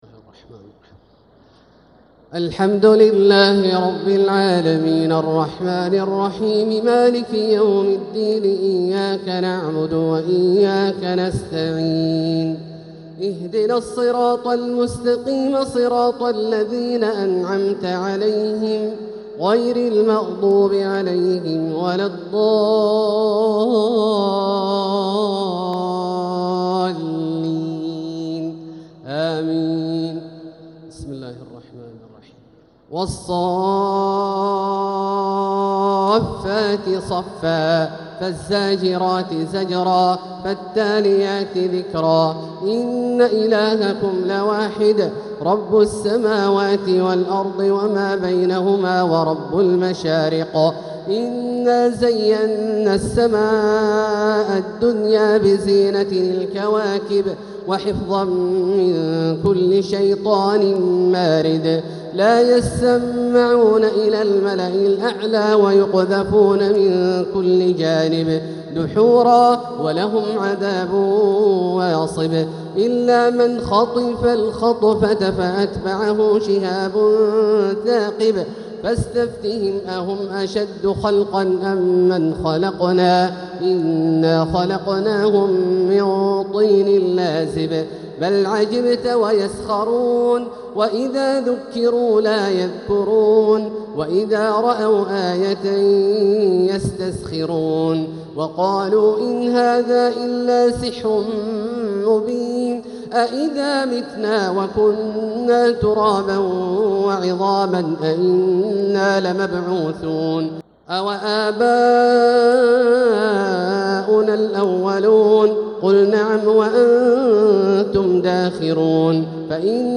تراويح ليلة 25 رمضان 1446هـ من سورتي الصافات كاملة و ص (1-26) | taraweeh 25th night Ramadan 1446H Surah As-Saaffaat and Saad > تراويح الحرم المكي عام 1446 🕋 > التراويح - تلاوات الحرمين